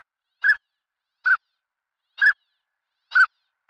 ÁNADE RABUDO (Anas acuta).
anade-rabudo.mp3